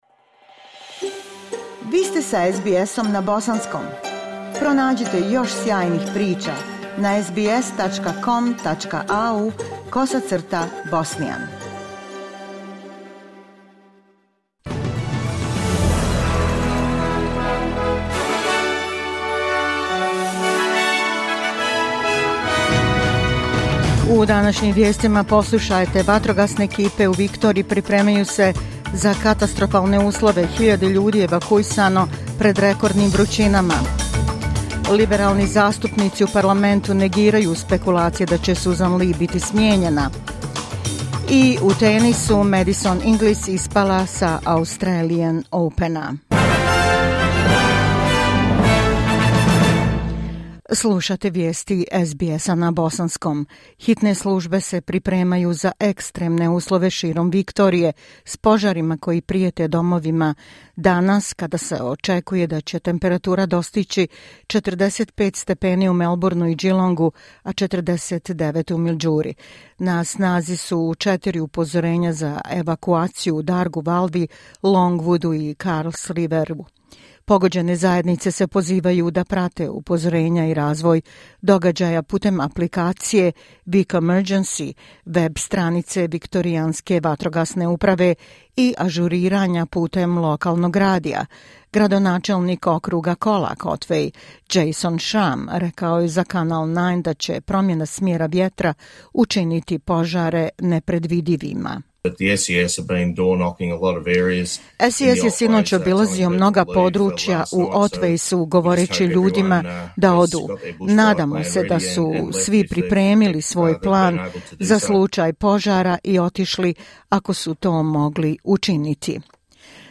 Vijesti za 27. januar 2026. godine